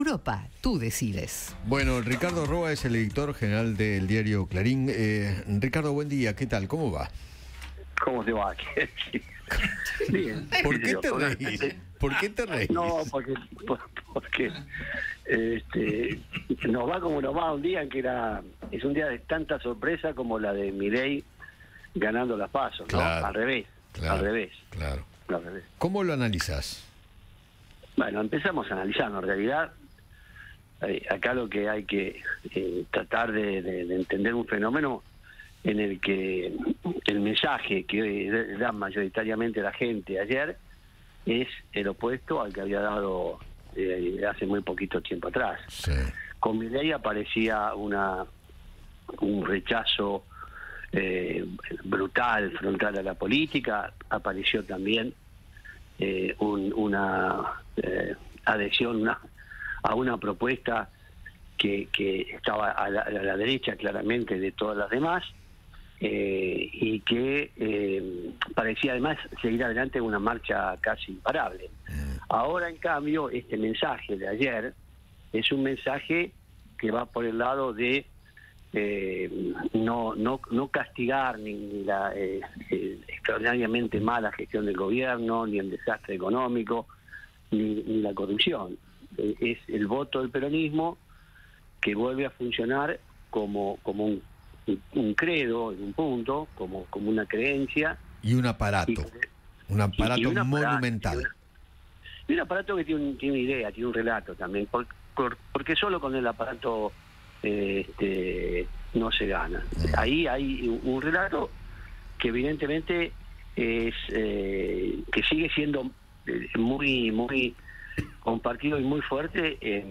dialogó con Eduardo Feinmann sobre el resultado de las elecciones y analizó la derrota de Juntos por el Cambio a nivel nacional.